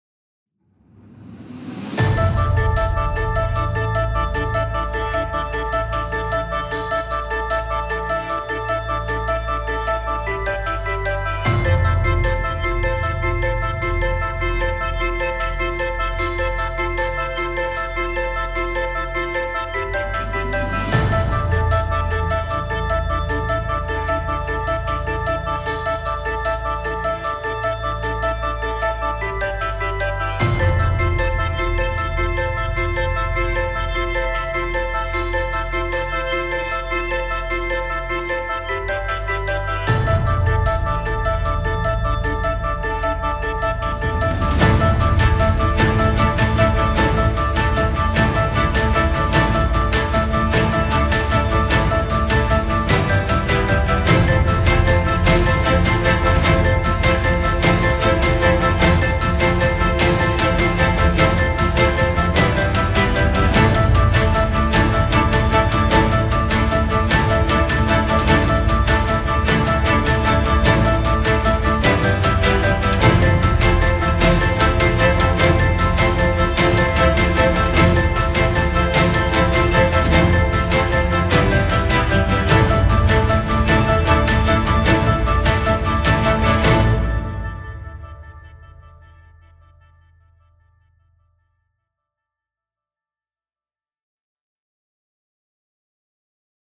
オーケストラ
シリアス
壮大